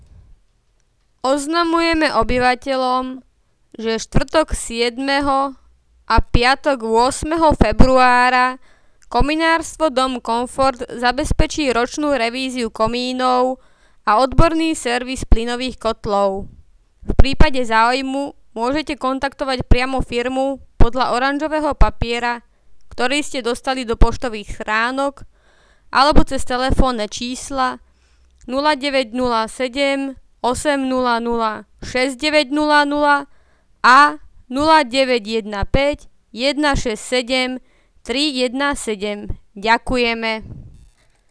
Hlásenia miestneho rozhlasu 1.2.2019 (Komerčné hlásenie)